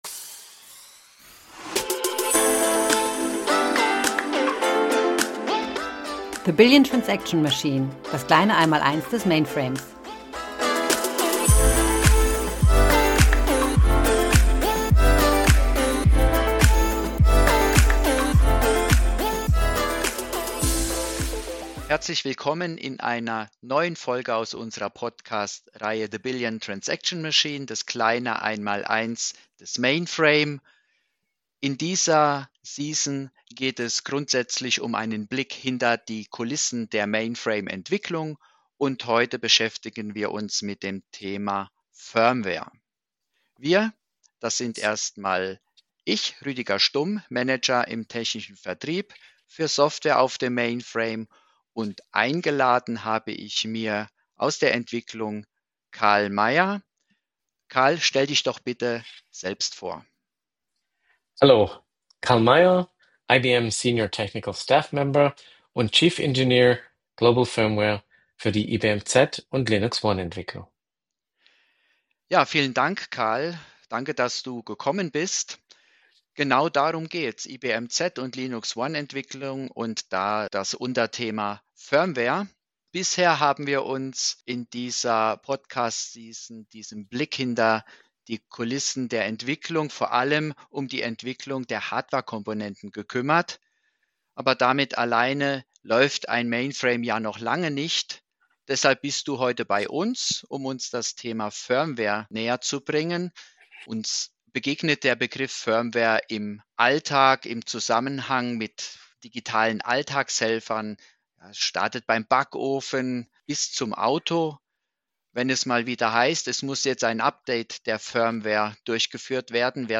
Ein Experte aus dem IBM Labor erlaubt uns einen interessanten Blick hinter die Kulissen!